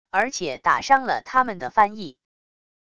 而且打伤了他们的翻译wav音频生成系统WAV Audio Player